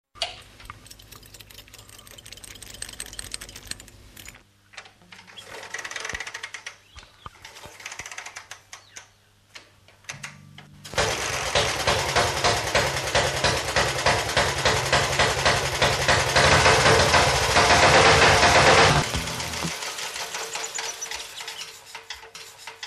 C'est un moteur à soupapes latérales avec un allumage par magnéto et une distribution par chaîne.
Pour  entendre ma machine au format  MP3 (358 Ko) :
favor_demare-2.mp3